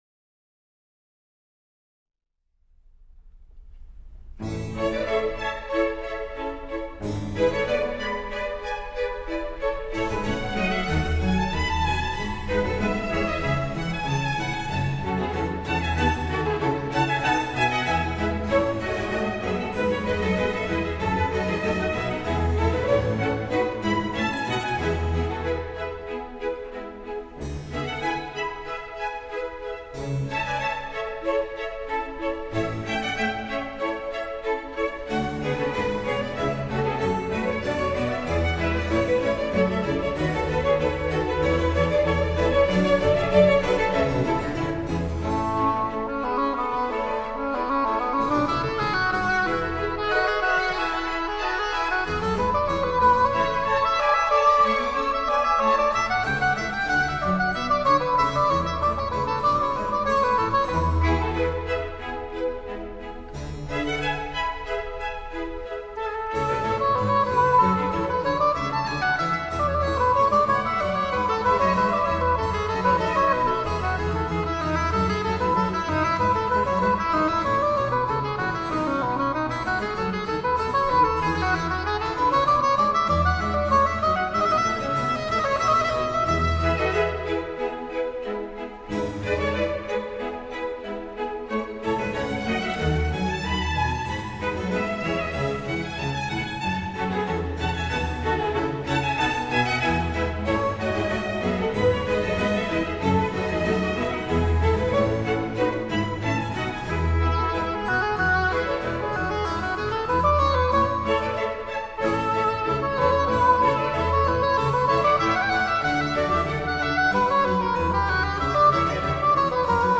古典双簧管